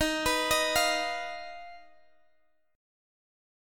Listen to D#sus2#5 strummed